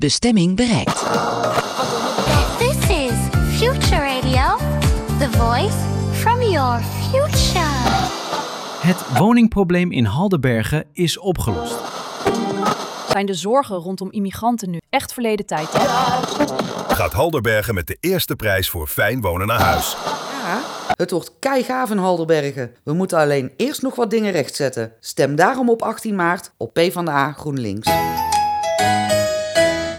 30secRadio_PvdA_GroenLinks.wav